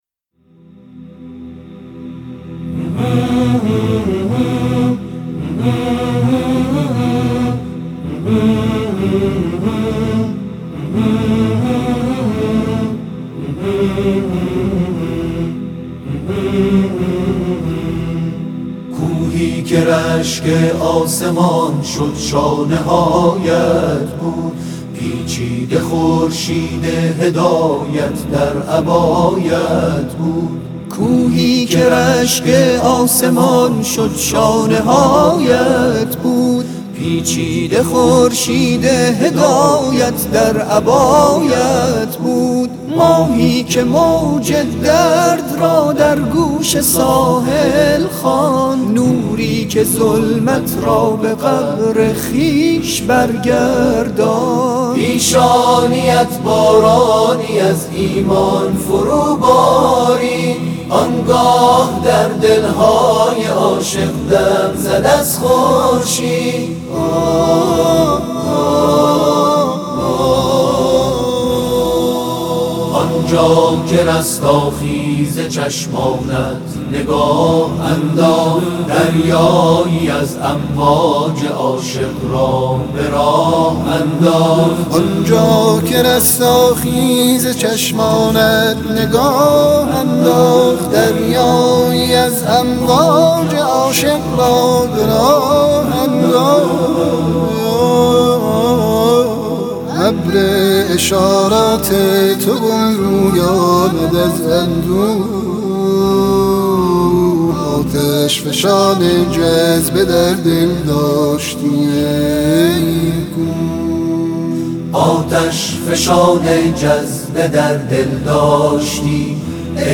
محفل انس با قرآن کریم جمعه، ۱۶ خردادماه، همراه با تلاوت قاریان بین‌المللی در آستان مقدس حضرت عبدالعظیم‌ الحسنی(ع) برگزار ‌شد.
یادآور می‌شود، این ویژه‌برنامه قرآنی با توجه به شیوع بیماری کرونا و محدودیت‌های موجود، بدون حضور جمعیت برگزار ‌و به صورت زنده از شبکه قرآن و معارف سیما پخش شد.